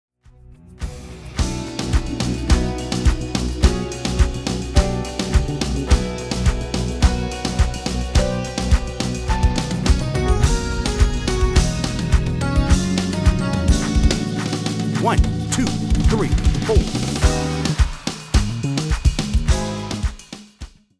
Key: B | Tempo: 106 BPM. https